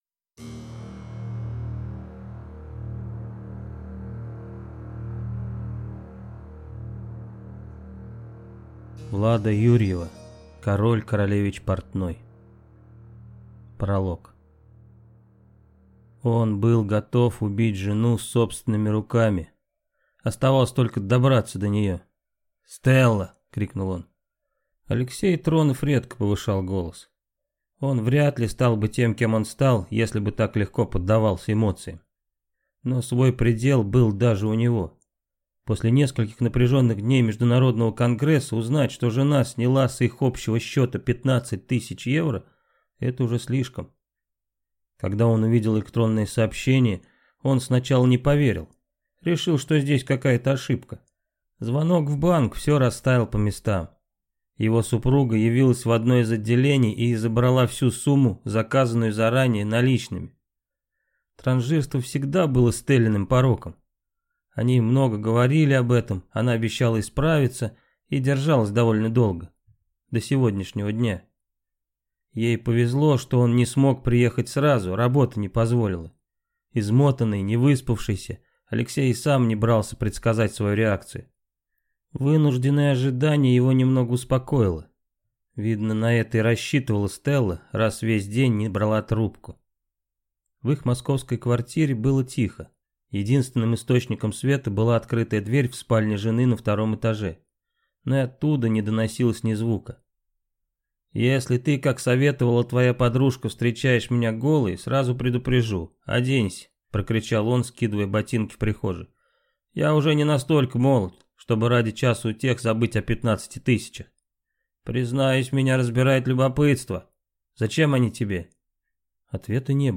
Аудиокнига Король, королевич, портной | Библиотека аудиокниг